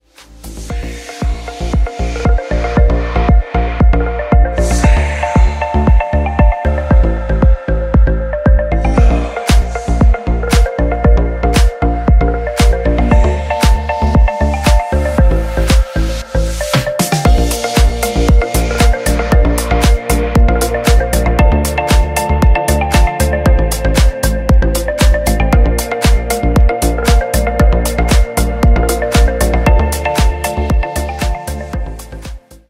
• Качество: 192, Stereo
deep house
атмосферные
Electronica
чувственные
расслабляющие
Клубный рингтон в стиле deep house.